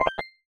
Universal UI SFX / Basic Menu Navigation
Menu_Navigation03_Options.wav